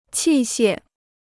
器械 (qì xiè): apparatus; instrument.